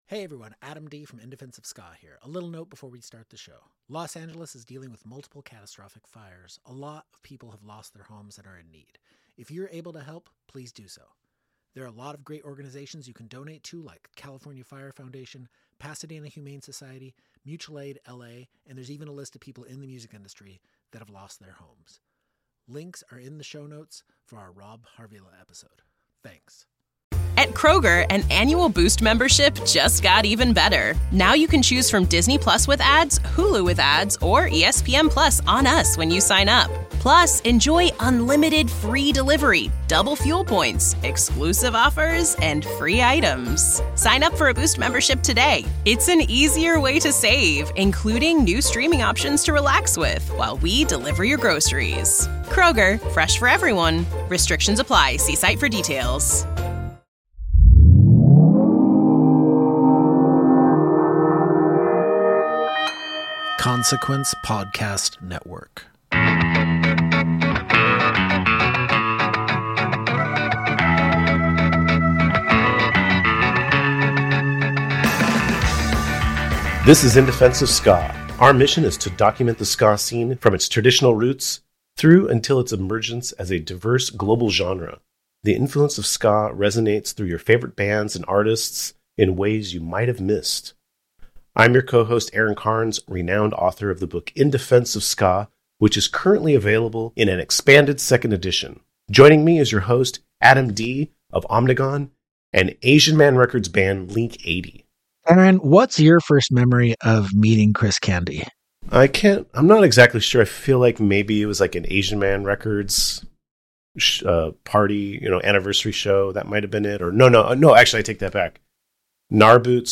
chat with people in and outside of the ska scene to tell its stories, show its pervasiveness in culture, and defend it to their last dying breath.